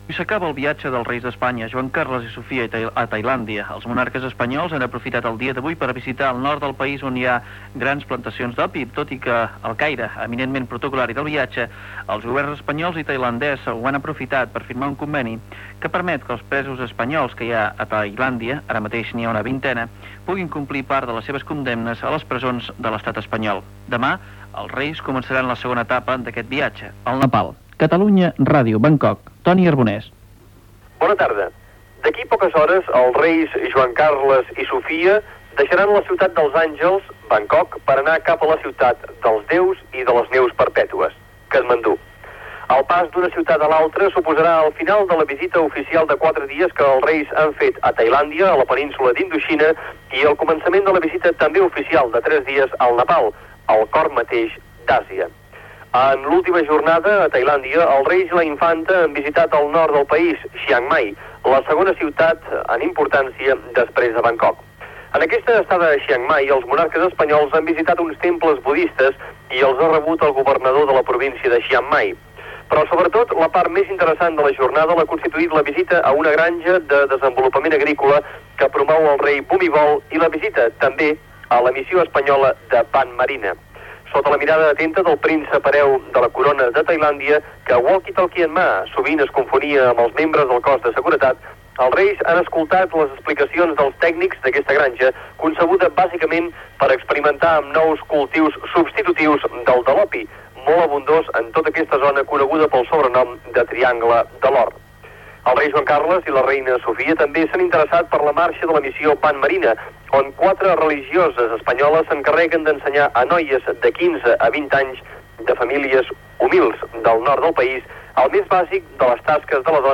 Informació, des de Bangkok, del quart dia de la visita dels reis d'Espanya Juan Carlos I i Sofia a Tailàndia
Informatiu